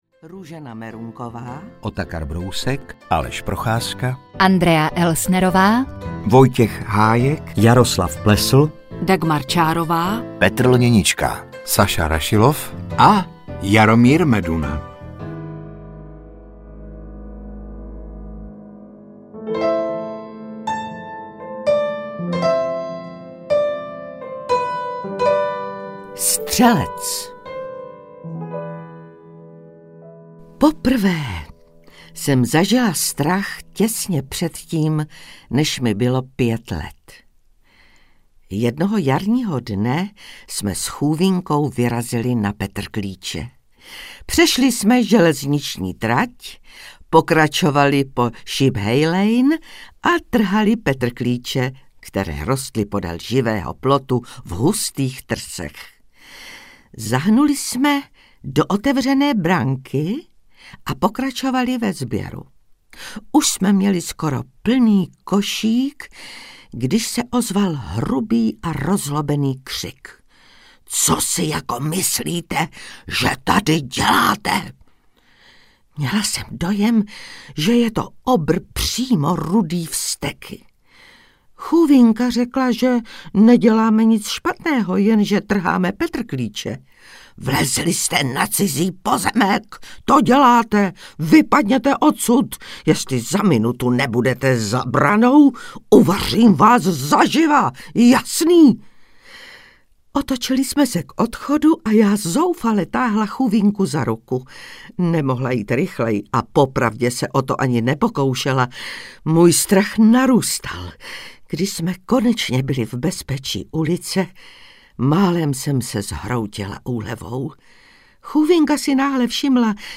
Zlověstné jaro audiokniha
Ukázka z knihy